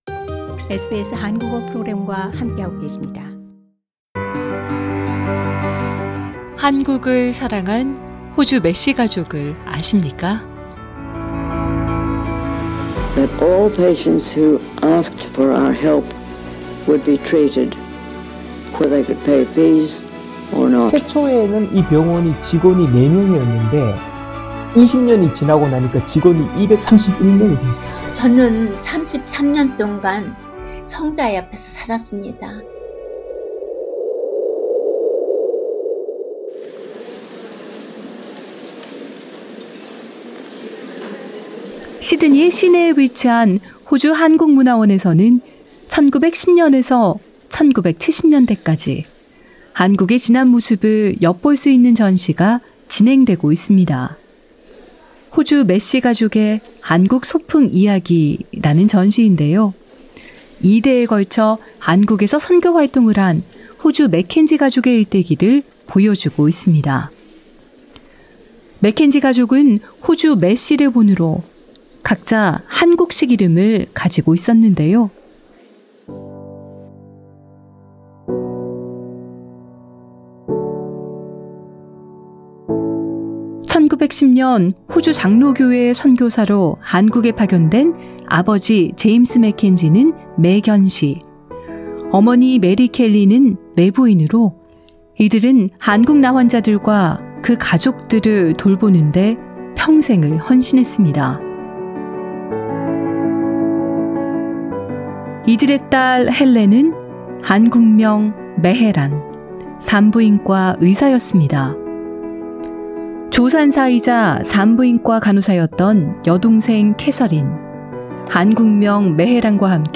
특집 다큐: 한국을 사랑한 호주 매 씨 가족을 아십니까?